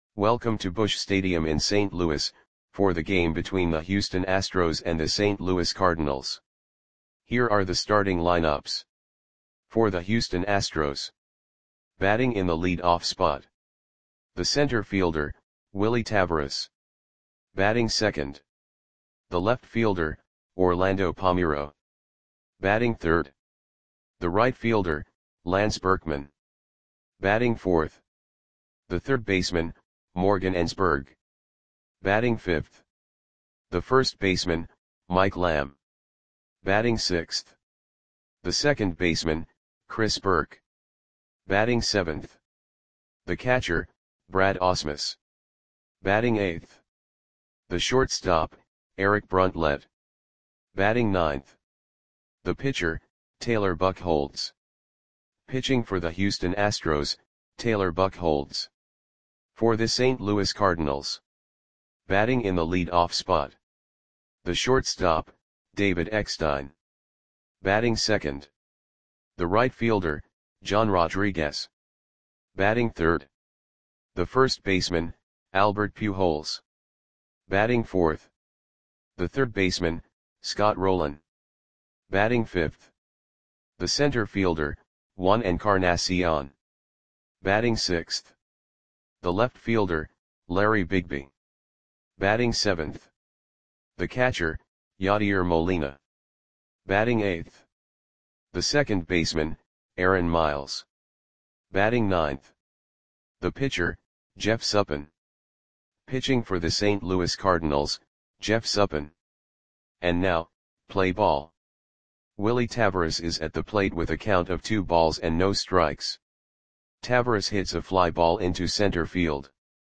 Audio Play-by-Play for St. Louis Cardinals on May 31, 2006